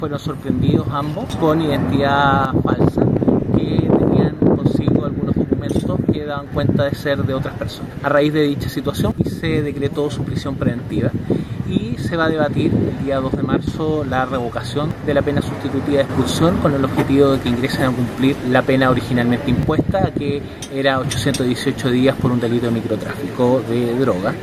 El Fiscal Jefe de Puerto Montt, Marcelo Maldonado, afirmó que en marzo se discutirá revocar su expulsión y decretar su prisión efectiva.